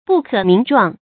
不可名狀 注音： ㄅㄨˋ ㄎㄜˇ ㄇㄧㄥˊ ㄓㄨㄤˋ 讀音讀法： 意思解釋： 名：說出；狀：形容。不能用語言來形容。